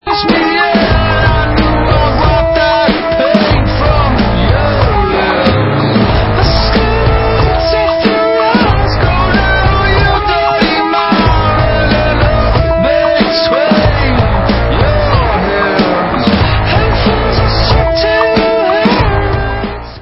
sledovat novinky v oddělení Hard Rock